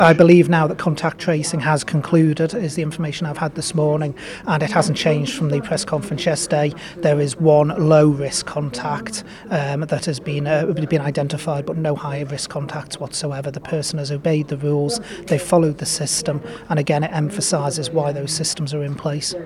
Health Minister David Ashford provides update
David Ashford, speaking to members of The Manx Legion Club in Douglas this lunchtime, says it shows the system is working: